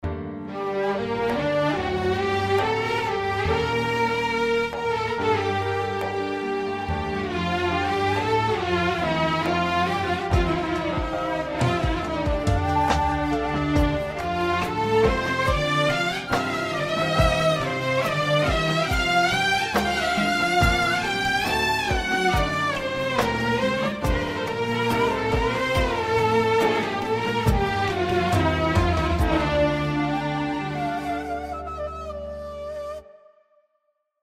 印度阿拉伯弦乐 HR Sounds HR Strings Gold Edition KONTAKT-音频fun
它采样了最逼真的弦乐音色，可以适用于各种风格的作曲，从西方古典音乐到东方民族音乐。它在世界各地的五个不同的录音室进行了录制，分别是土耳其、伊拉克、叙利亚、埃及和德国。
HR Sounds HR Strings Gold Edition 提供了 86 种多重音色，涵盖了阿拉伯和印度等多种风格的演奏方式。
HR-Strings-Gold-Edition.mp3